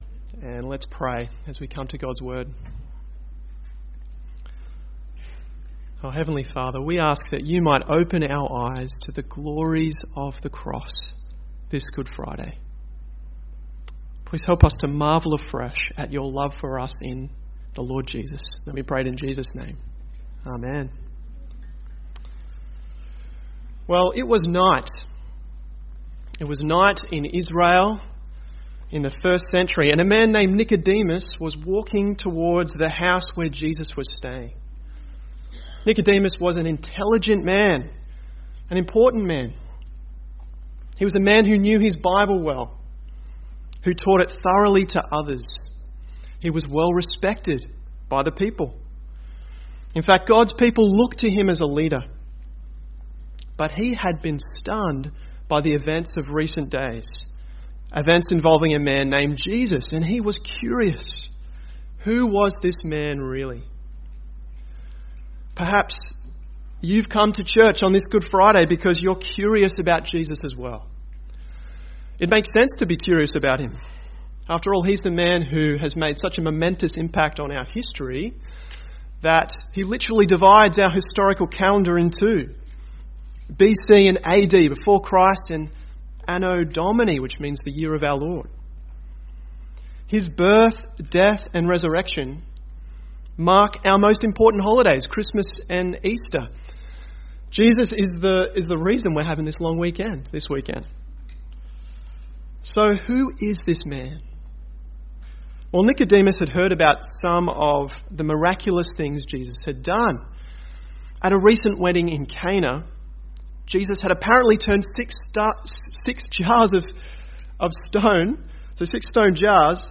Good Friday – 2 April 2021